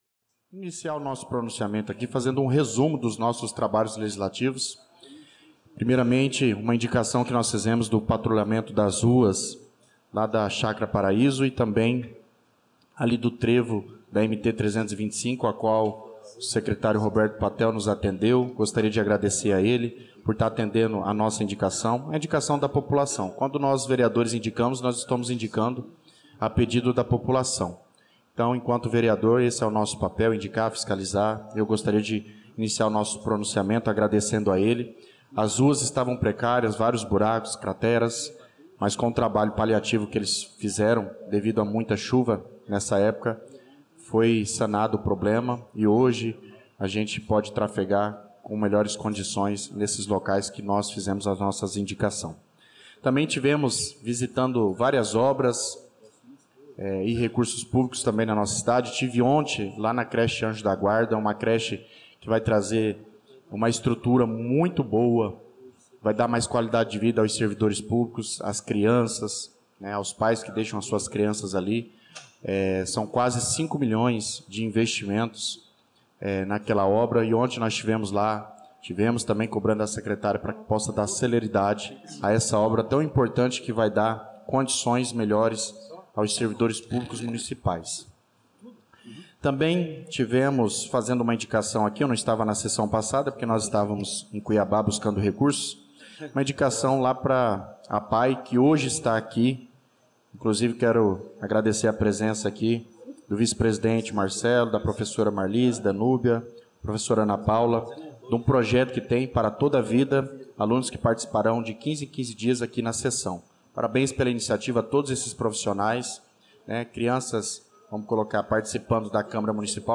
Pronunciamento do vereador Douglas Teixeira na Sessão Ordinária do dia 18/02/2025